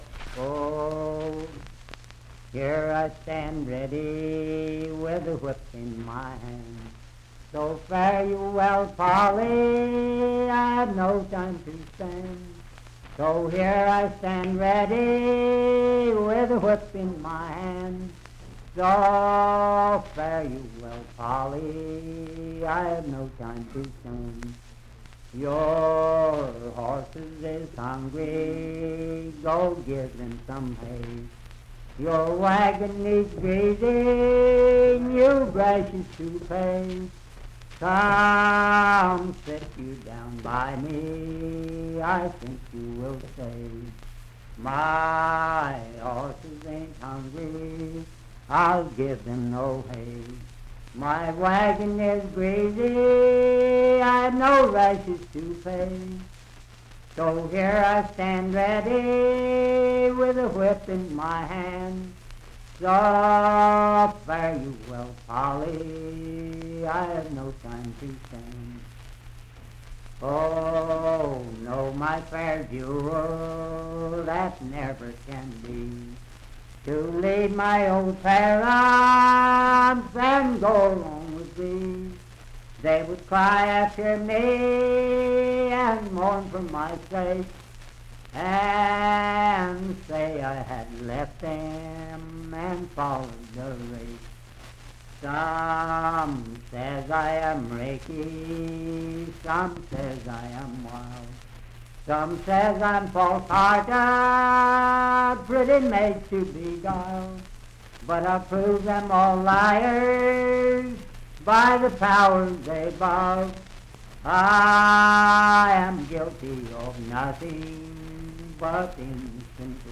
Unaccompanied vocal music
Voice (sung)
Wood County (W. Va.), Parkersburg (W. Va.)